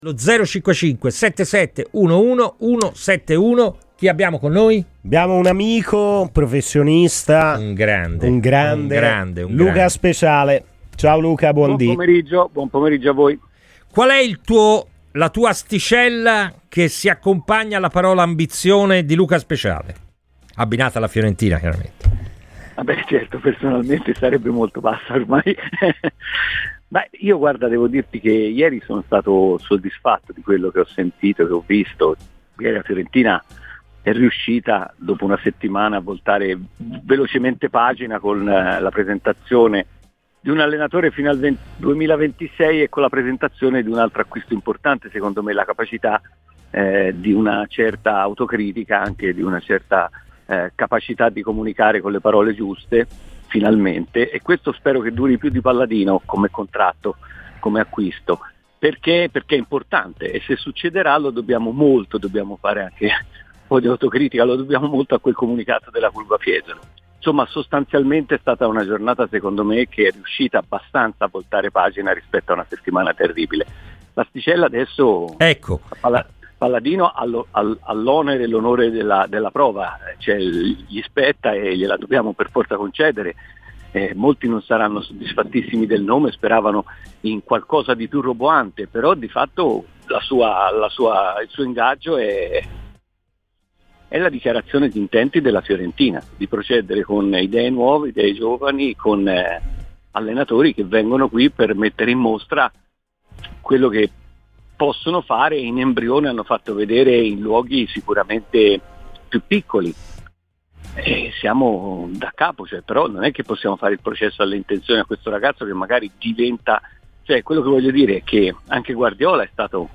giornalista di La7